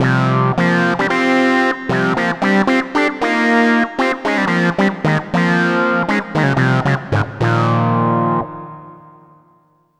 AQUA RIFF.wav